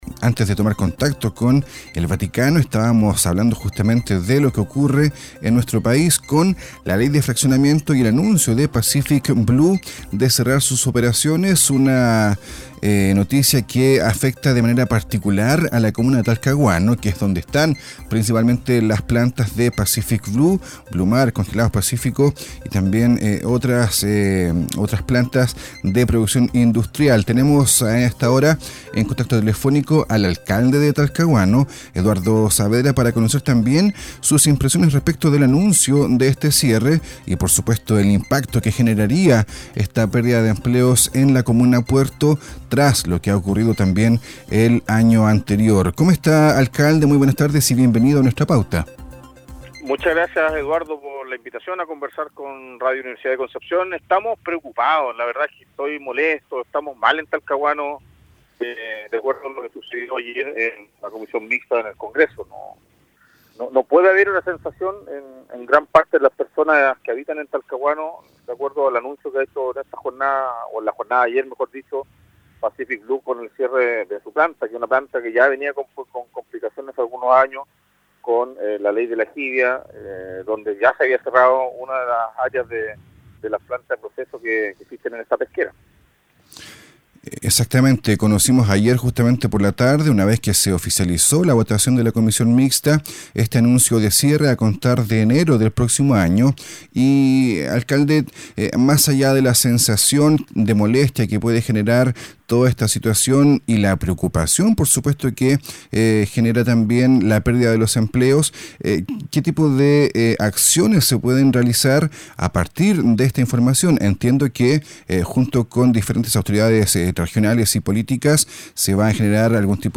En Nuestra Pauta, conversamos con el alcalde Eduardo Saavedra sobre este anuncio de cierre.